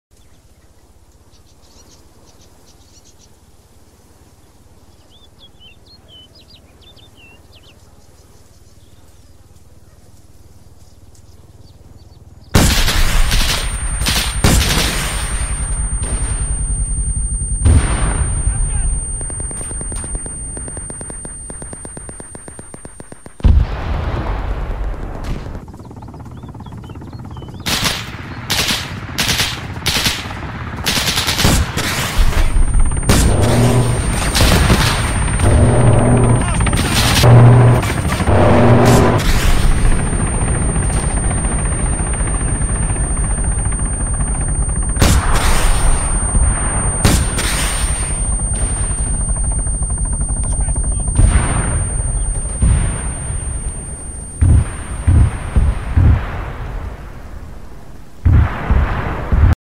C RAM Air Defense System In Sound Effects Free Download